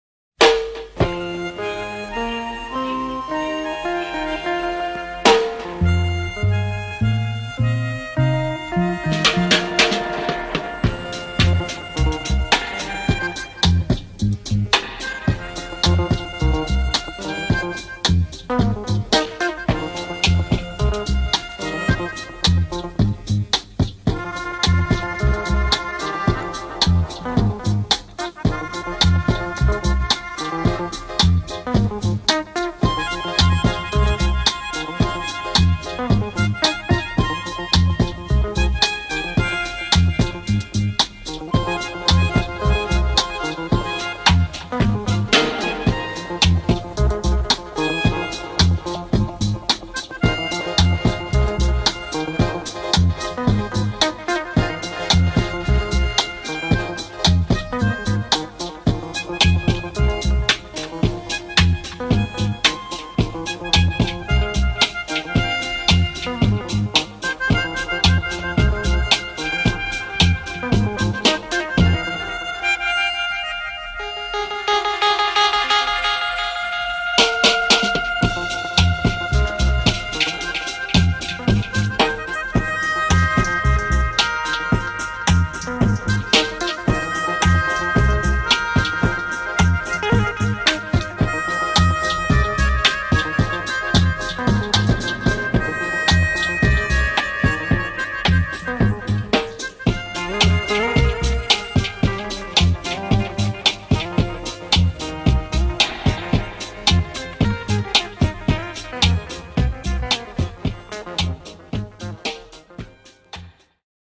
instrumental and dub composition